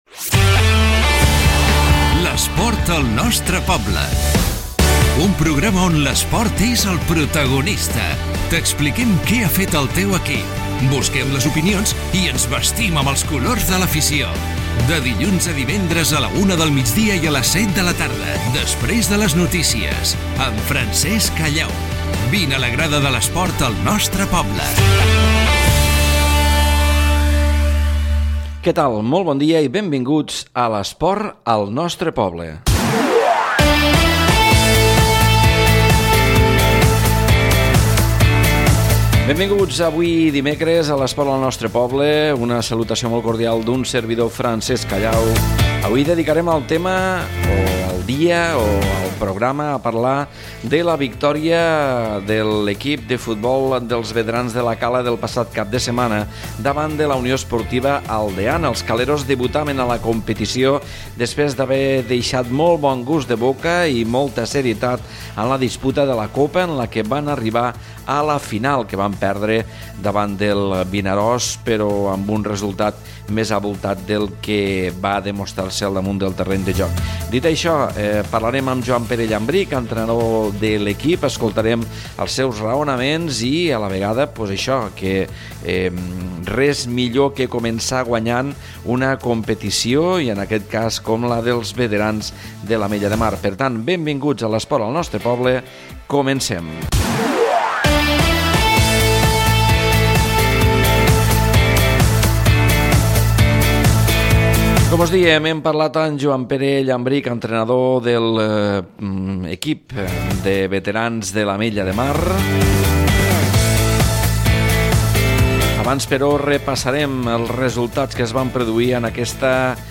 Careta del programa, presentació, els veterans de l'Ametlla de Mar guanyen el seu primer partit de la Lliga de Veterans. Resultats dels partits de futbol.
Esportiu